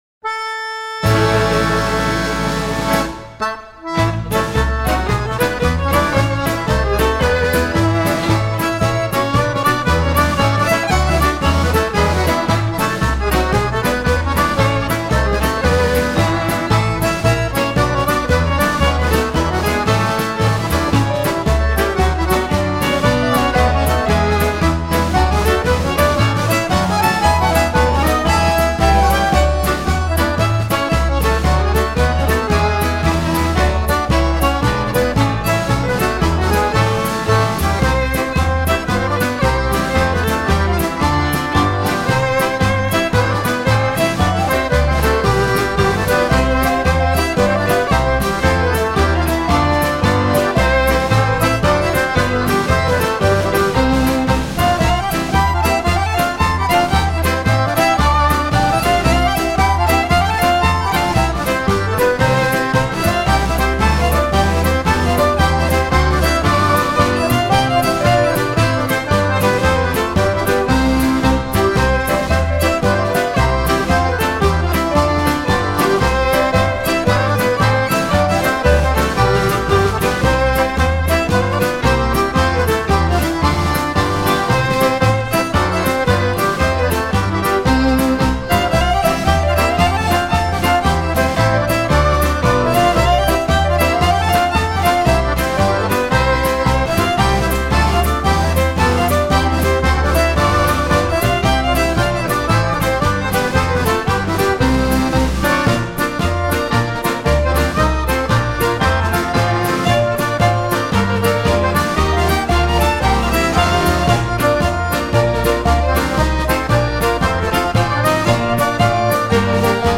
Here is some cheery upbeat Scottish Dance music to enjoy.